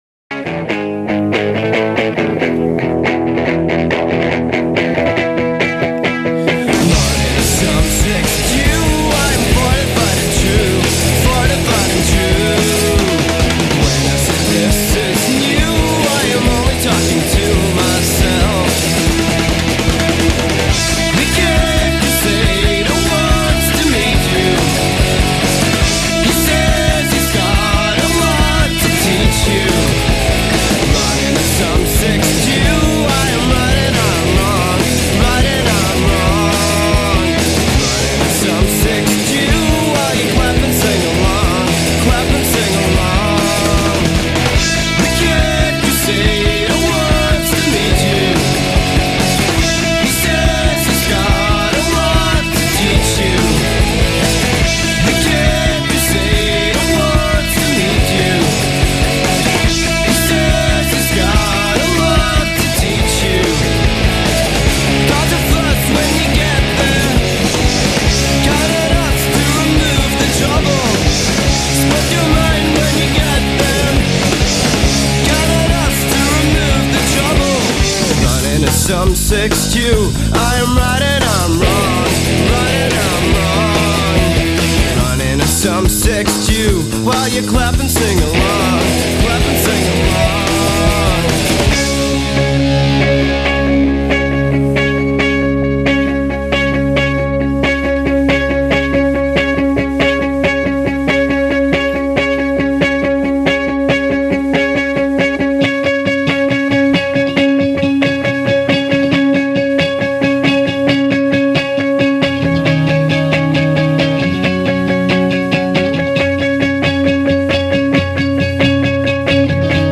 Sounds of 90's Glasgow.